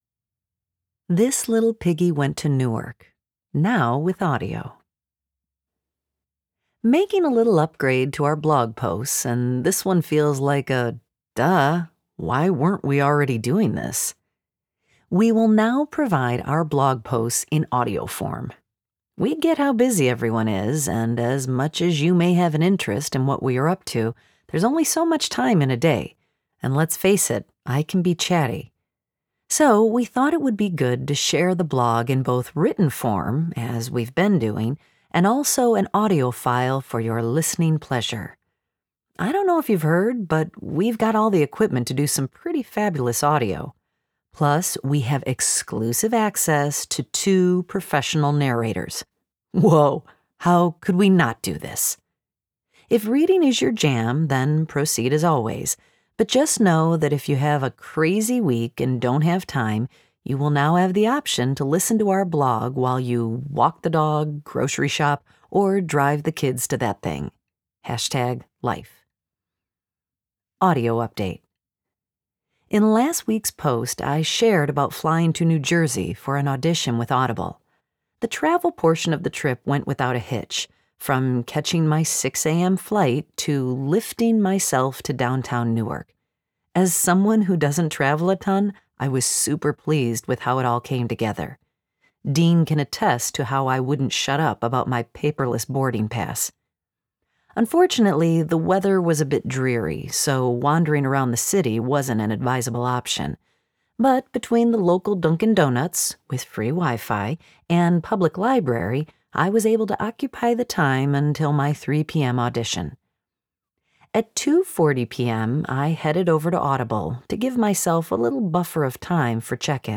We will now provide our blog posts in audio form!